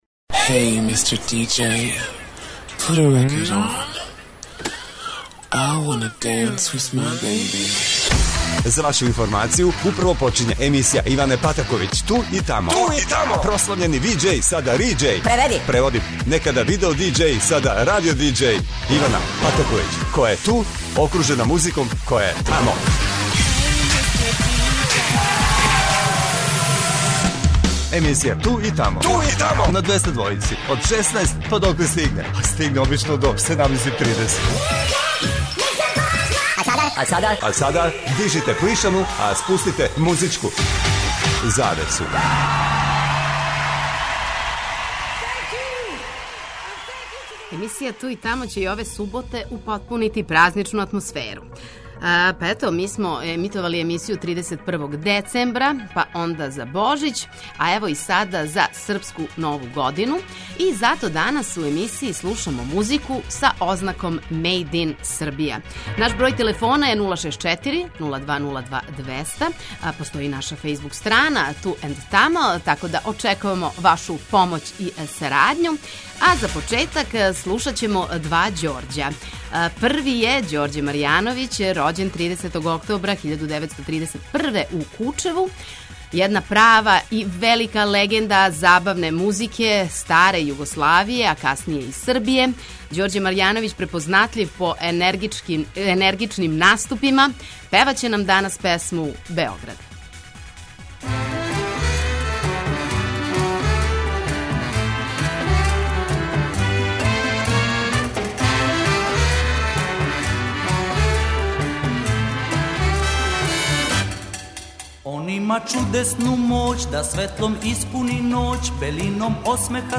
Емисија 'Ту и тамо' ће и ове суботе употпунити празничну атмосферу.
Правила је друштво слушаоцима Двестадвојке последњег дана старе године, за Божић, а сада и за Српску нову годину. И зато у најсвежијој "Ту и тамо" авантури слушамо само извођаче из Србије!!!